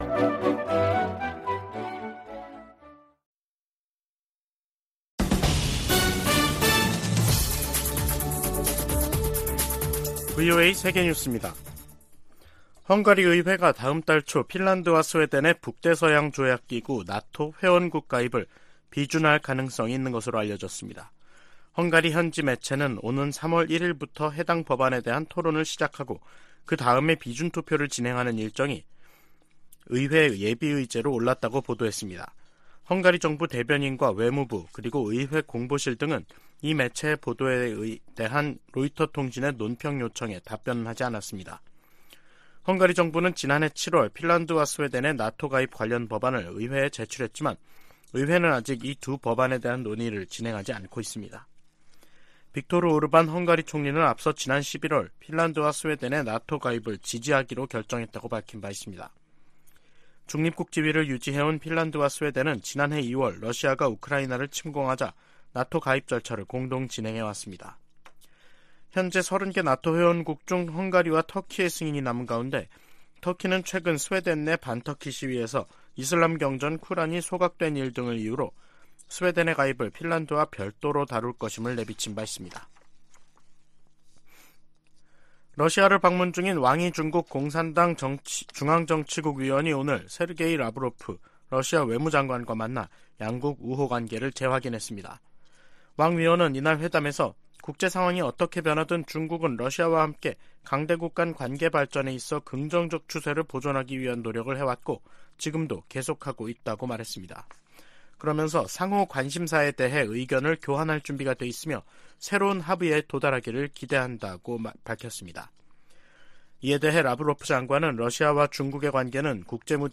VOA 한국어 간판 뉴스 프로그램 '뉴스 투데이', 2023년 2월 22일 2부 방송입니다. 미 하원 군사위원장이 북한의 미사일 위협에 맞서 본토 미사일 방어망을 서둘러 확충할 것을 바이든 행정부에 촉구했습니다. 한국 군 당국은 북한이 대륙간탄도미사일(ICBM)의 모든 발사 능력을 보유하고 있고 정상각도 시험발사 시점을 재고 있는 단계라고 밝혔습니다. 영국과 프랑스, 독일을 비롯한 주요 국가들이 북한의 ICBM 발사를 일제히 규탄했습니다.